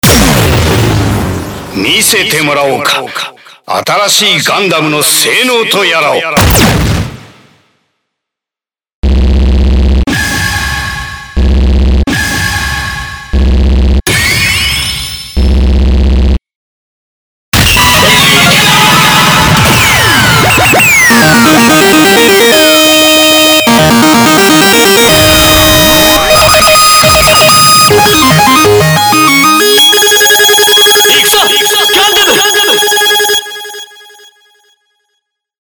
『機動戦士ガンダムUC』より3000FEVER昇格時の音声を内蔵した目覚まし時計が登場!